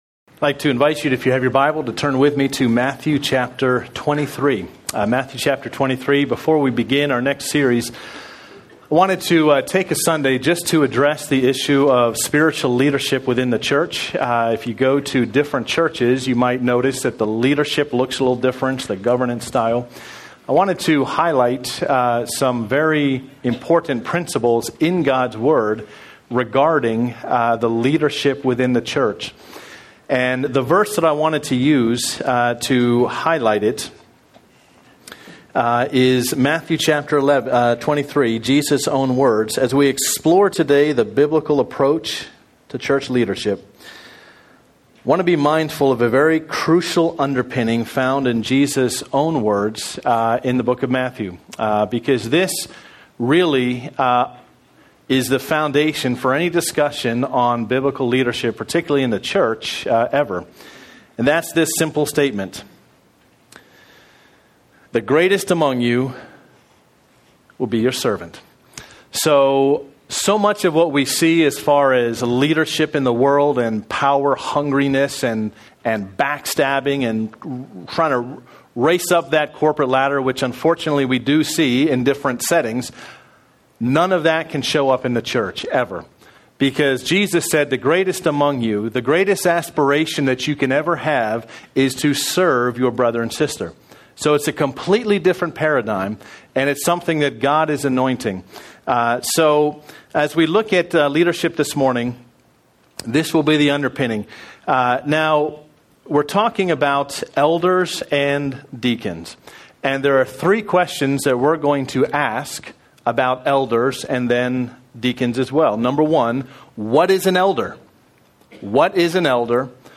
Sermon Notes What Your Church Leaders Look Like?